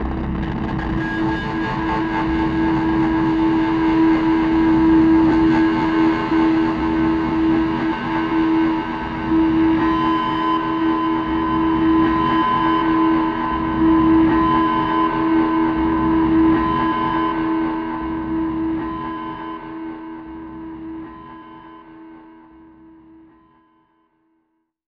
screaming Indian brass
Category 🗣 Voices
ambience bell dark ding distortion electro electronic feedback sound effect free sound royalty free Voices